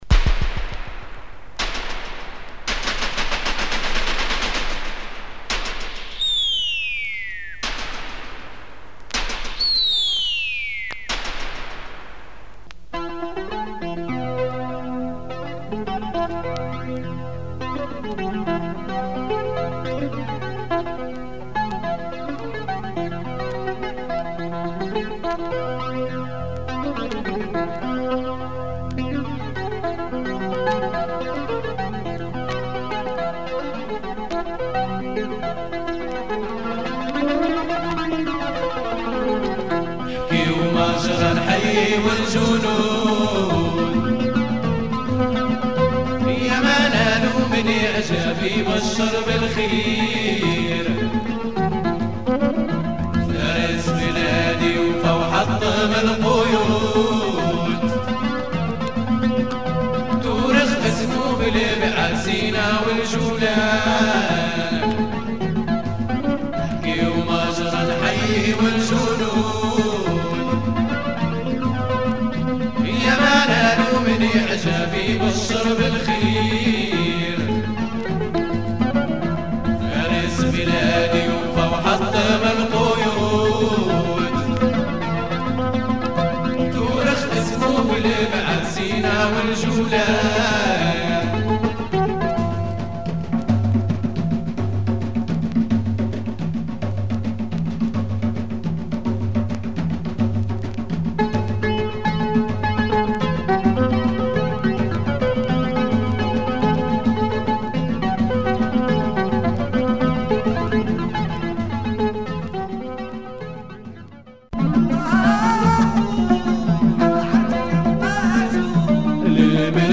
Trippy and delicate Moroccan music.